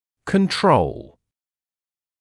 [kən’trəul][кэн’троул]контроль; регуляция, управление; регулировать, управлять; контрольная группа (при сравнительных исследованиях); контрольный